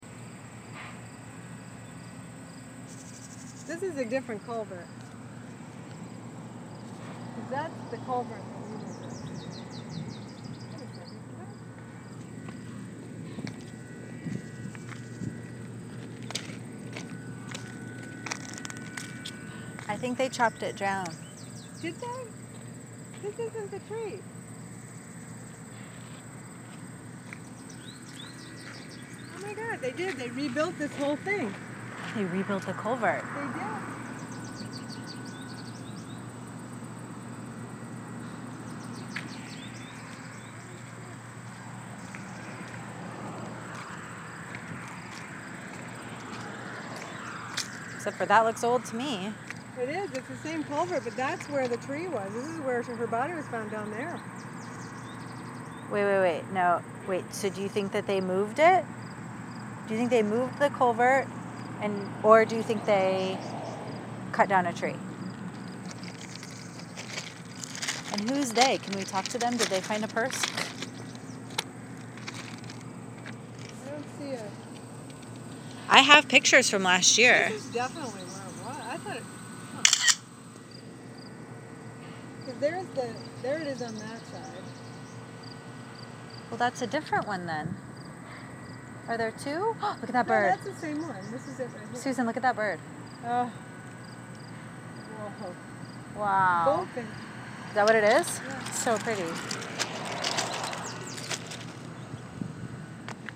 There’s something about the shock in my voice.